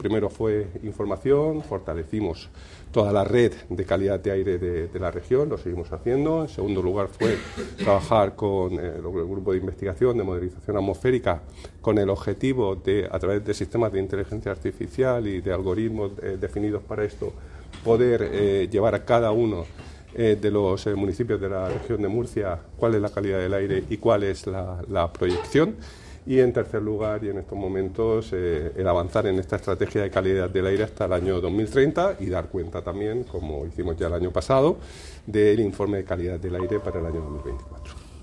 El consejero de Medio Ambiente, Universidades, Investigación y Mar Menor, Juan María Vázquez, sobre los tres puntos importantes de la II Jornada Calidad del Aire de la Región de Murcia.